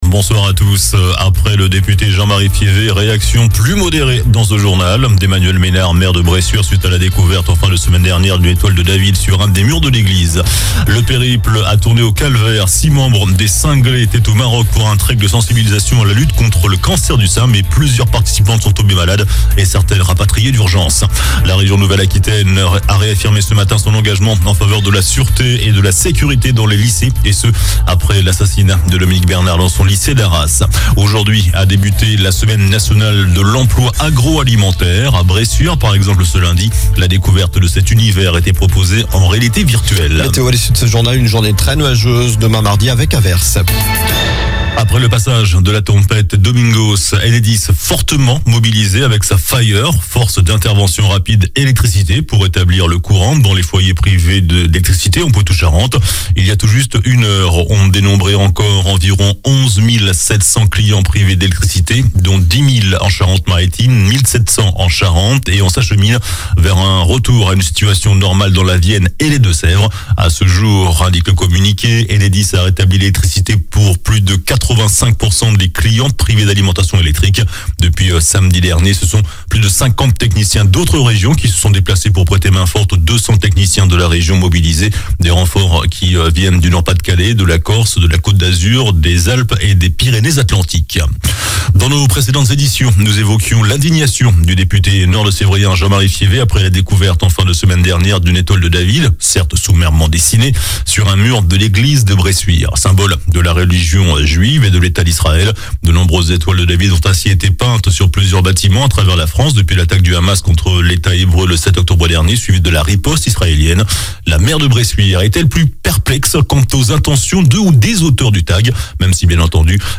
JOURNAL DU LUNDI 06 NOVEMBR ( SOIR )